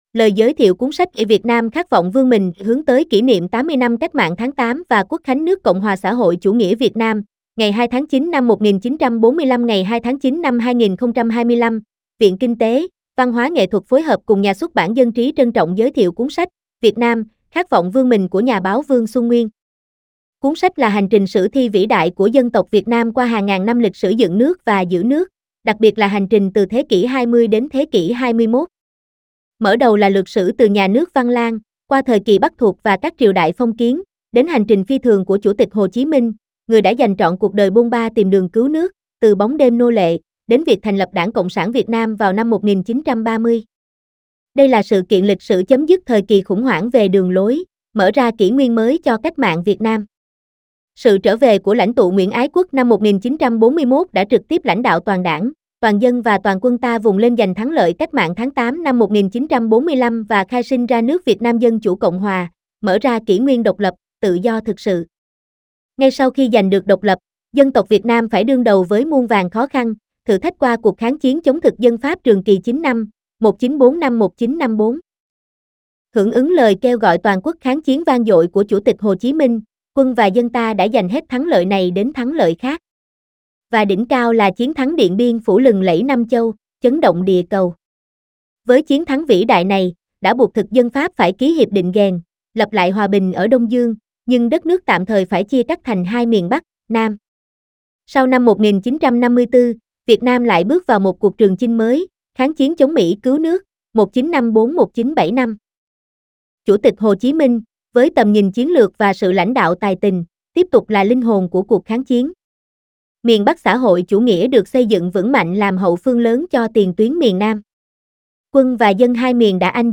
SÁCH NÓI: "VIỆT NAM KHÁT VỌNG VƯƠN MÌNH"